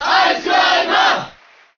Ice_Climbers_Cheer_Japanese_SSBM.ogg